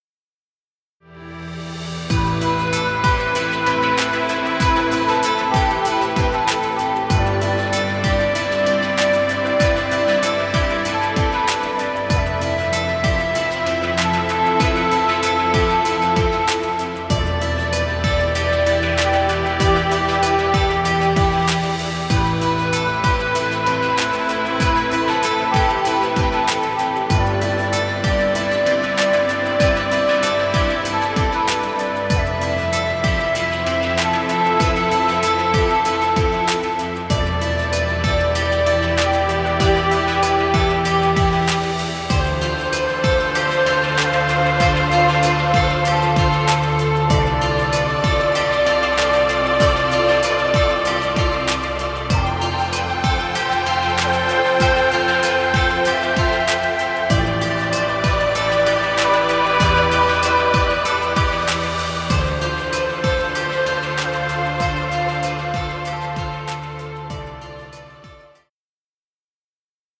Chillout music. Background music Royalty Free.